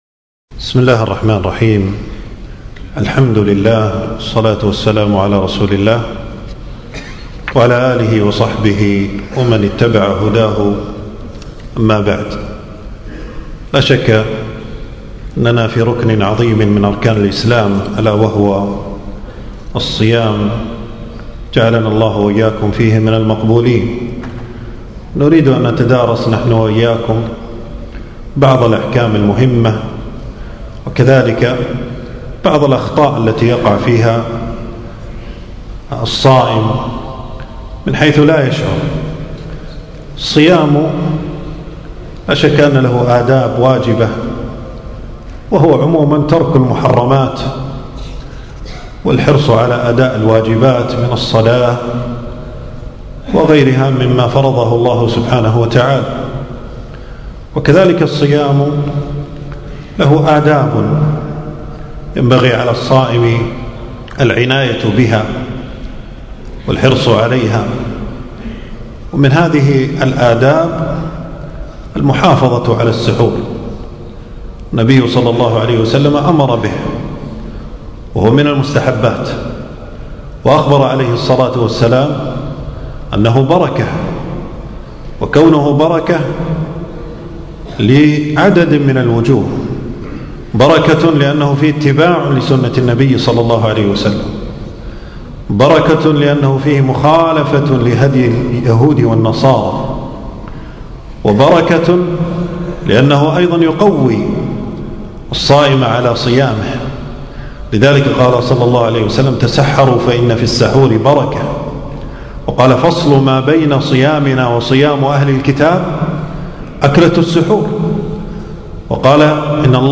تنزيل تنزيل التفريغ محاضرة بعنوان: المجالس الفقهية الرمضانية - المجلس الثاني.
في مسجد أبي سلمة بن عبدالرحمن بن عوف.